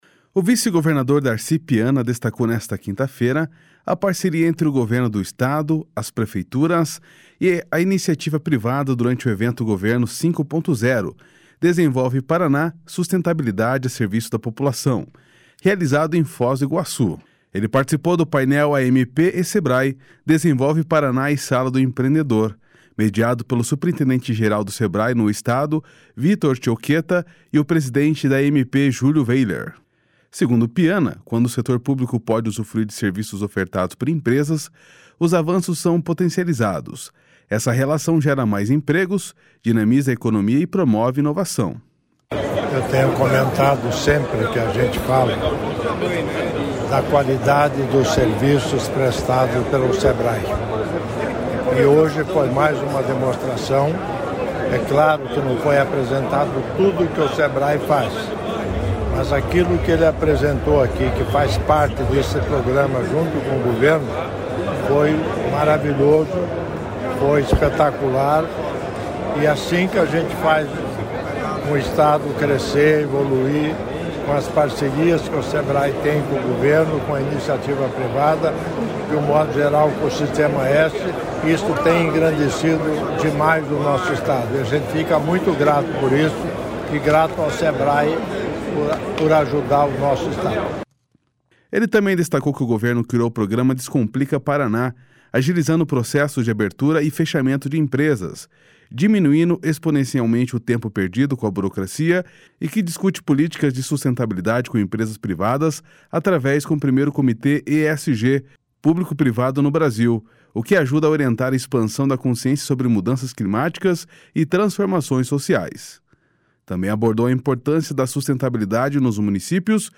Essa relação gera mais empregos, dinamiza a economia e promove inovação.//SONORA DARCI PIANA//